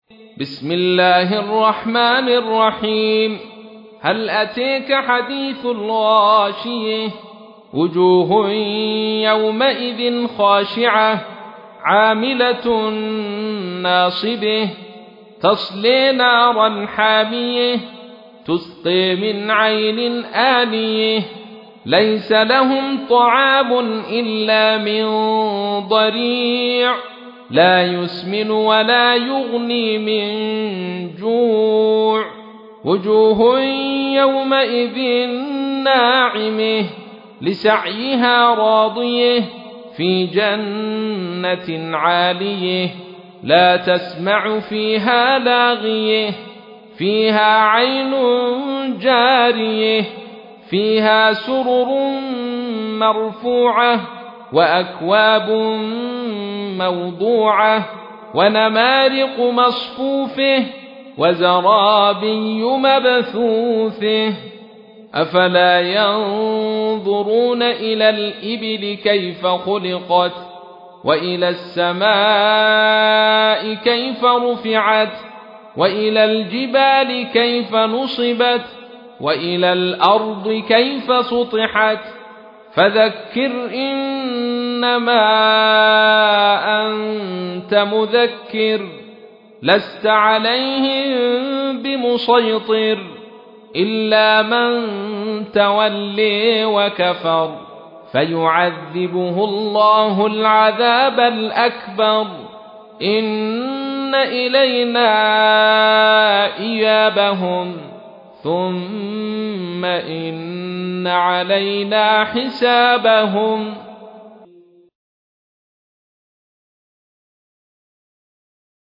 تحميل : 88. سورة الغاشية / القارئ عبد الرشيد صوفي / القرآن الكريم / موقع يا حسين